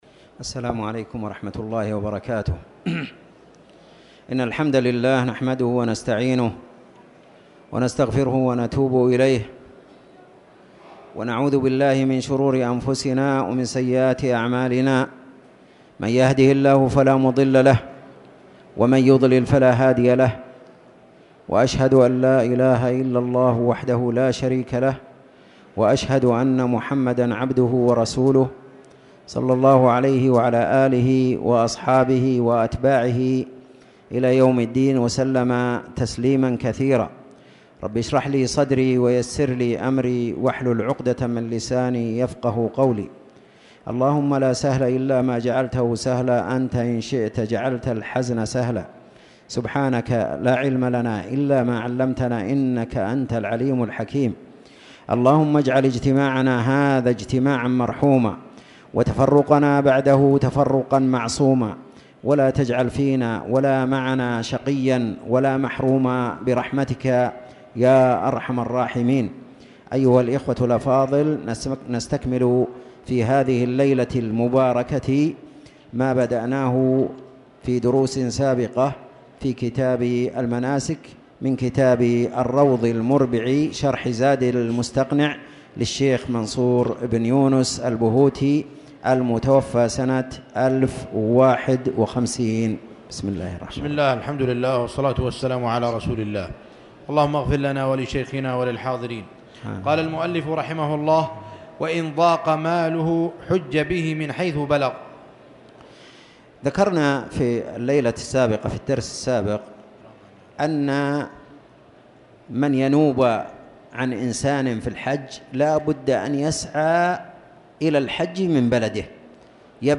تاريخ النشر ٢٢ جمادى الآخرة ١٤٣٨ هـ المكان: المسجد الحرام الشيخ